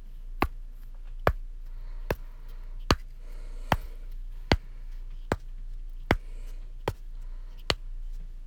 Knocking on brick.wav